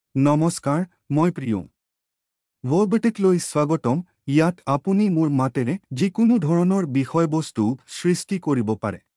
PriyomMale Assamese AI voice
Priyom is a male AI voice for Assamese (India).
Voice sample
Listen to Priyom's male Assamese voice.
Male
Priyom delivers clear pronunciation with authentic India Assamese intonation, making your content sound professionally produced.